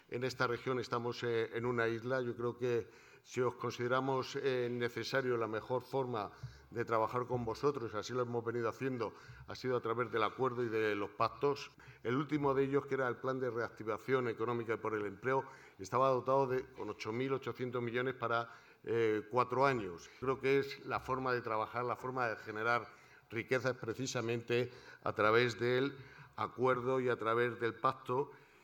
>> En el acto de entrega de los 26 Galardones Empresariales de FEDETO